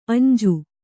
♪ añju